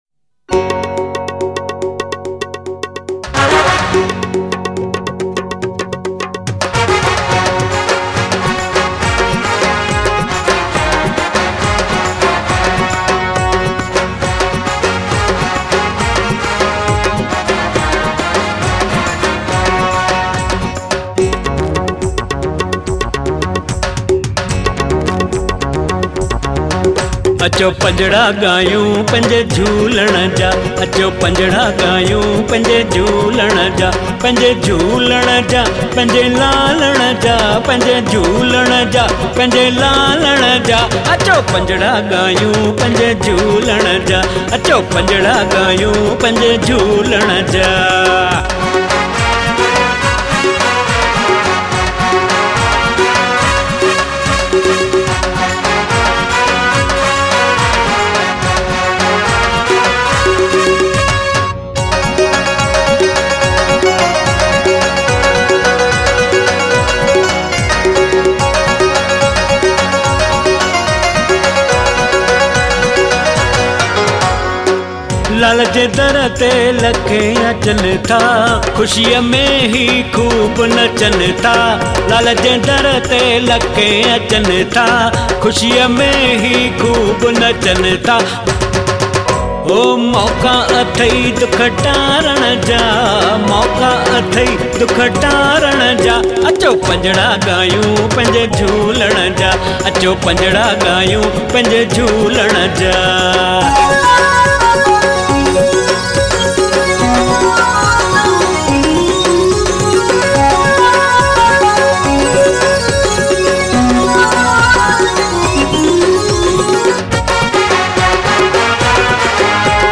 Sindhi Bhajans